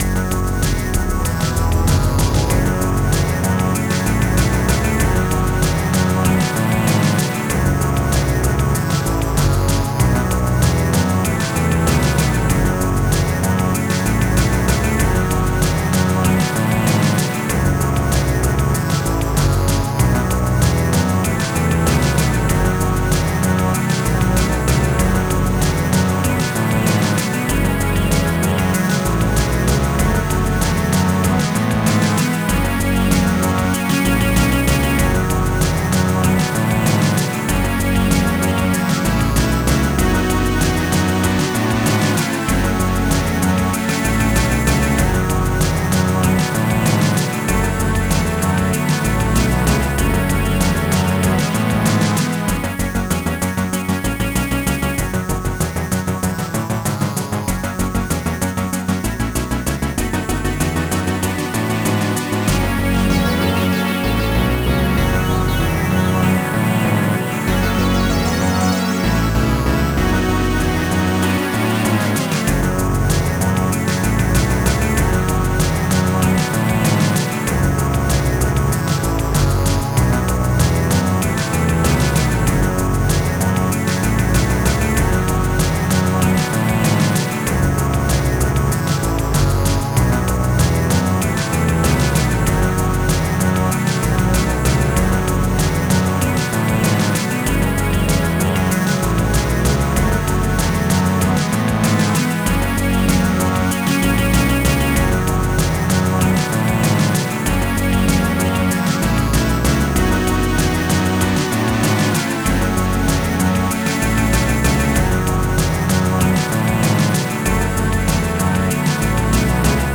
Style: Amiga